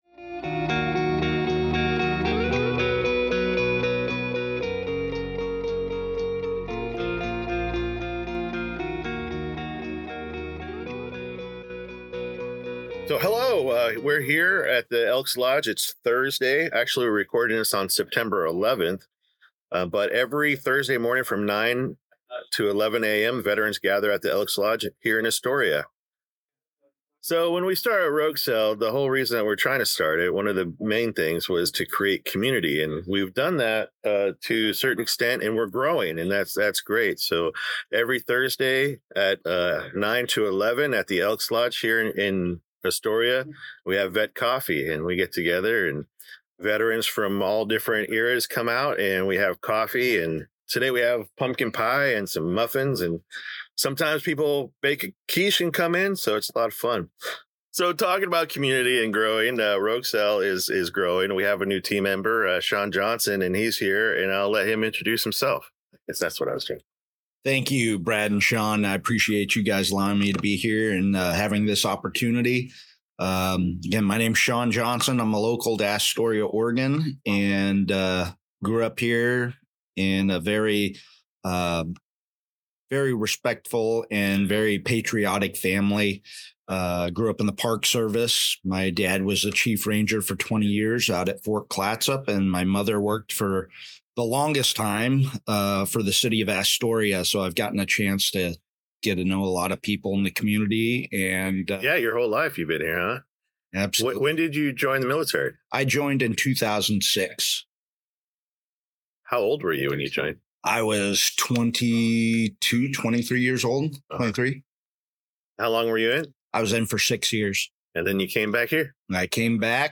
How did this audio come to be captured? In this episode, Rogue Cell was recorded at a veteran coffee group, hosted every Thursday from 9 am to 11 am at the Elks Lodge in Astoria, on the second floor.